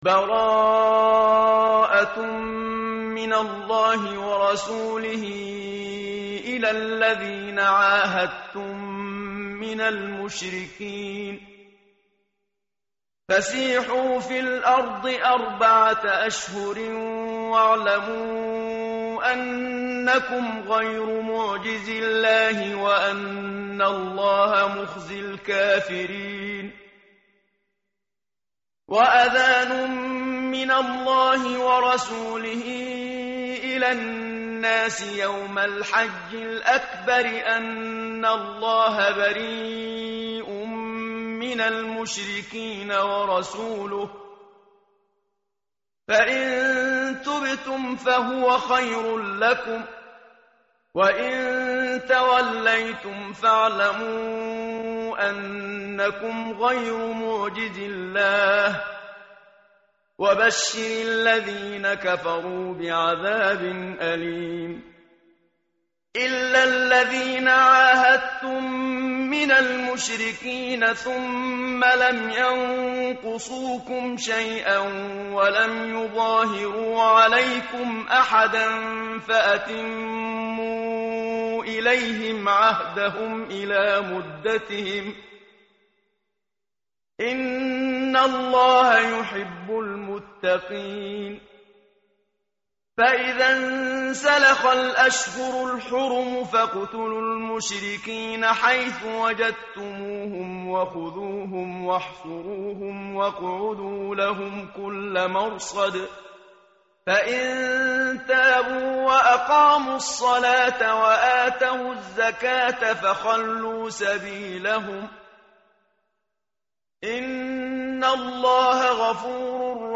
متن قرآن همراه باتلاوت قرآن و ترجمه
tartil_menshavi_page_187.mp3